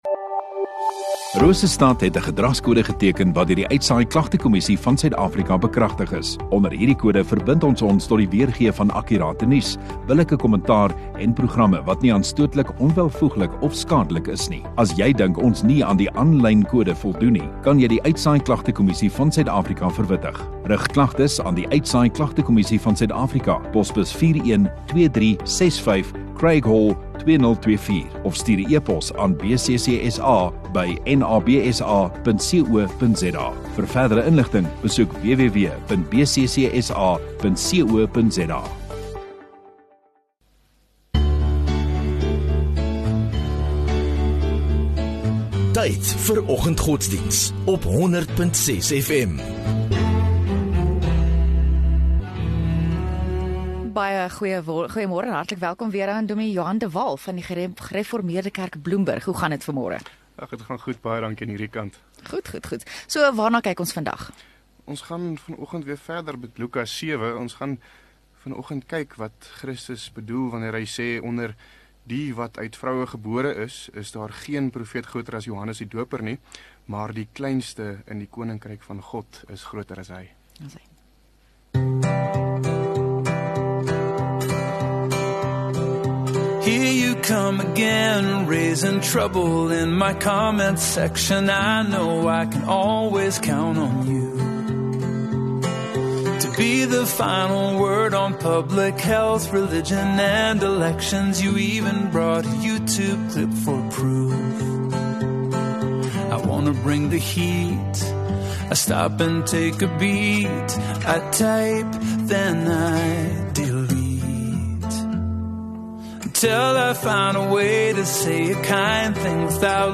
30 Jul Dinsdag Oggenddiens